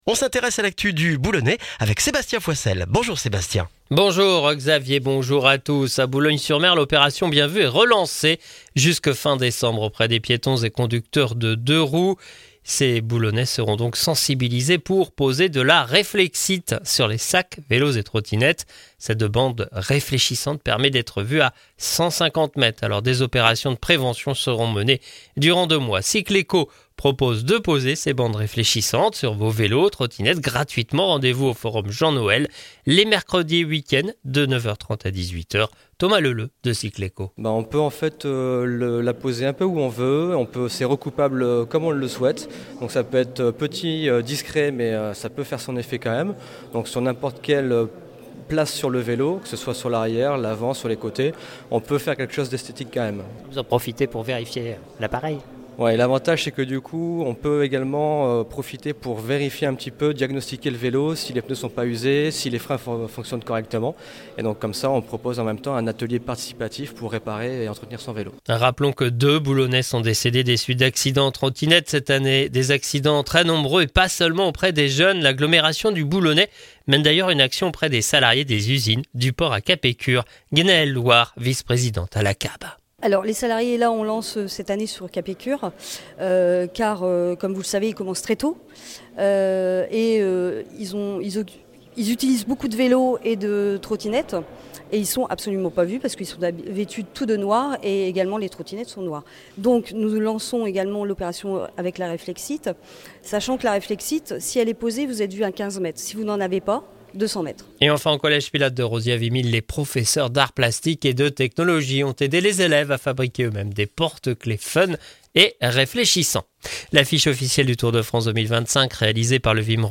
Le journal du mardi 5 novembre dans le Boulonnais